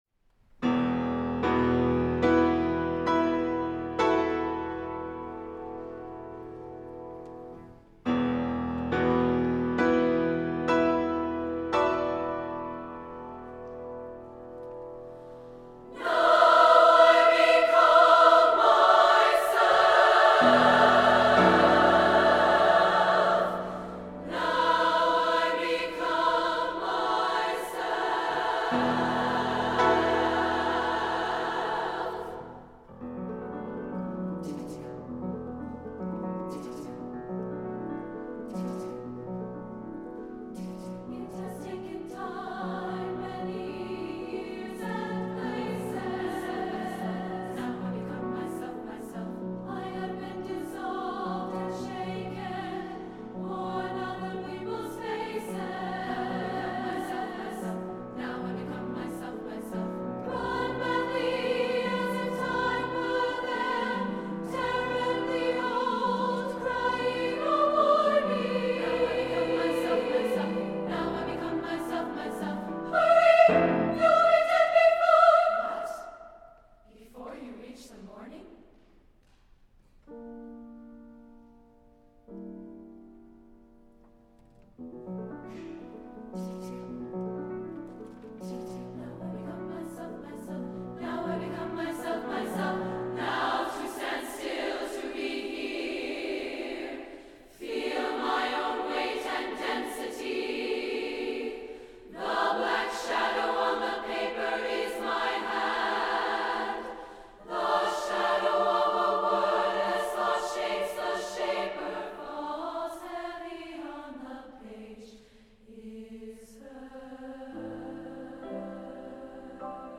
for SSA Chorus and Piano (1999)